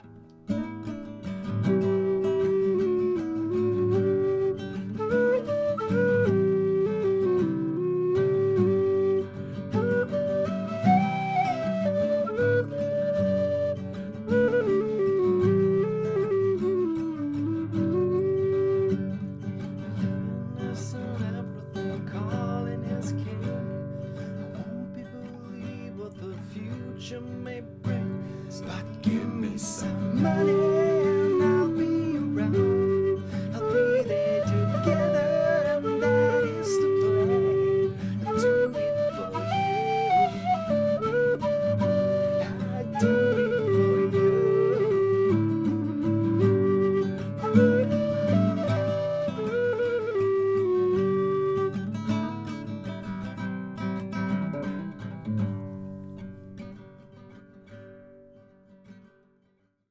Lånade ett par mickar av polarn för att testa mot de jag brukar använda. Lite halvimprovisation med nylonsträngat och low whistle.
Första mickparet;
Mickarna placerade mitt i rummet på en meters avstånd. Vi sitter på ca. 30cm avstånd från mickarna på en linje.